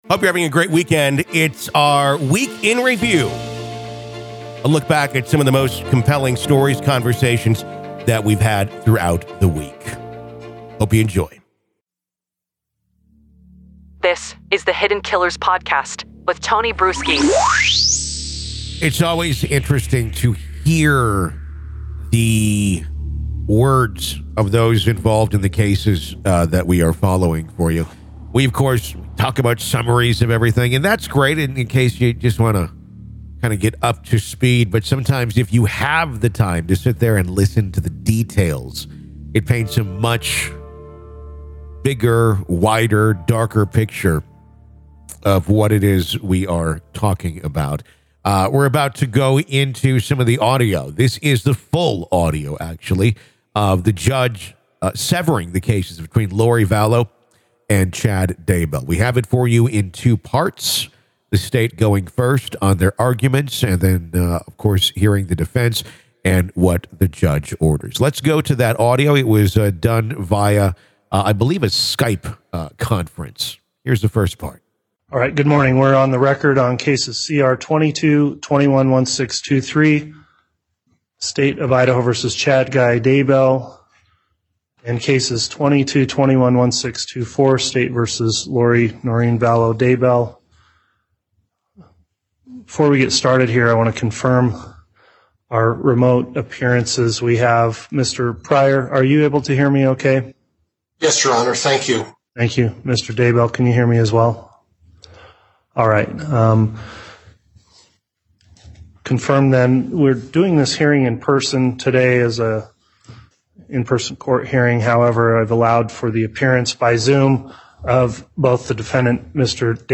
Full Courtroom Coverage